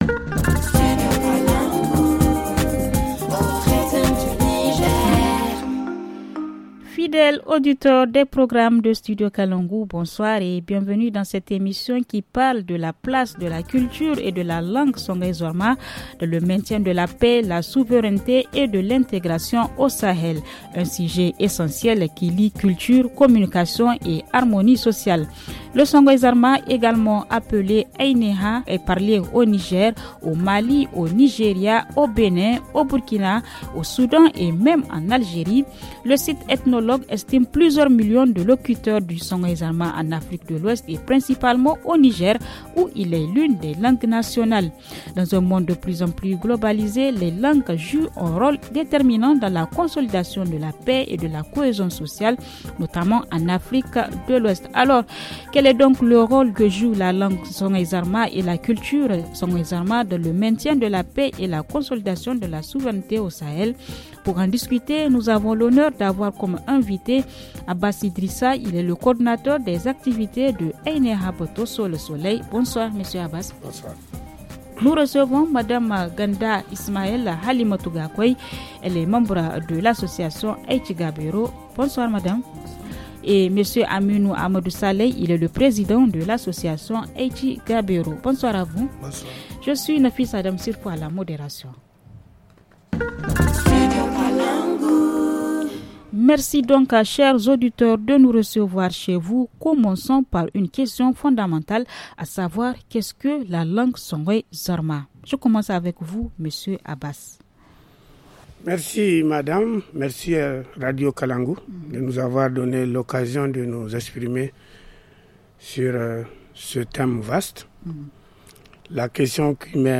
Le forum en français